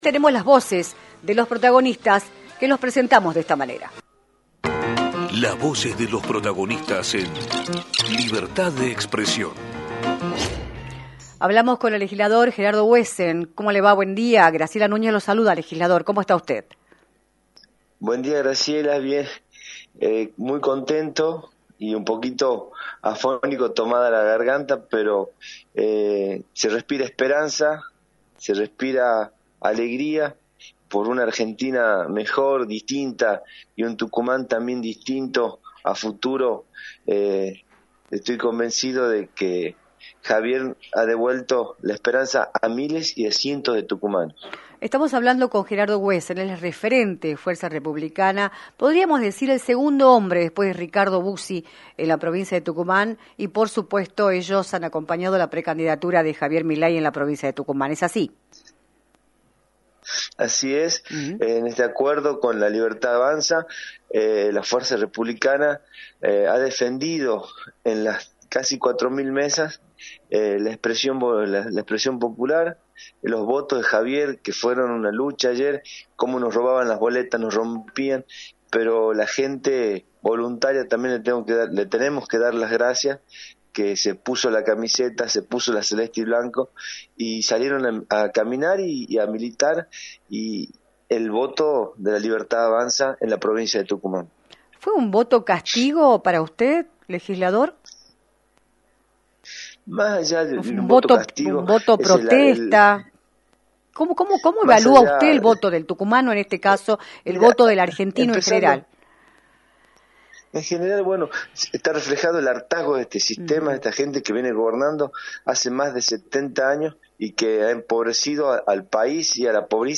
Gerardo Huesen, Legislador del partido Fuerza Republicana, analizó en “Libertad de Expresión”, por la 106.9, los resultados de las elecciones PASO y el triunfo de Javier Milei en las primarias.